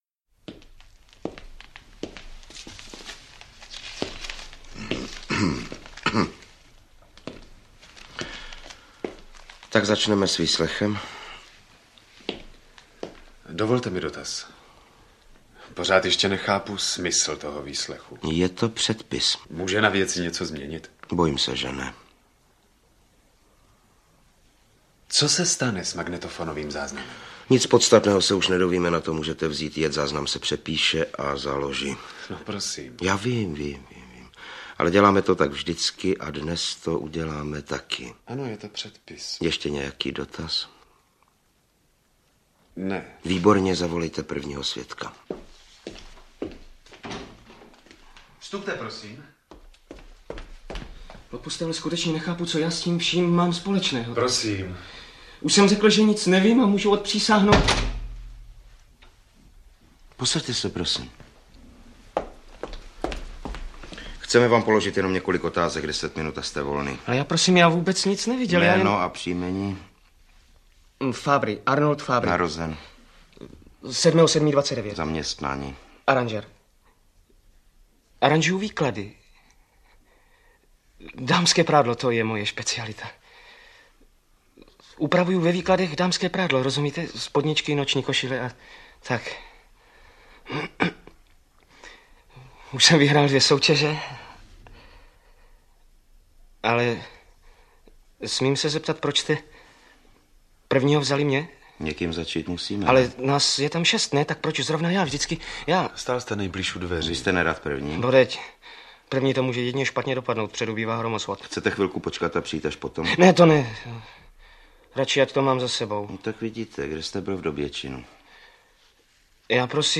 Audio kniha
Ukázka z knihy
Například zachycením způsobu chůze, jakou jednotliví svědkové do místnosti vstupují.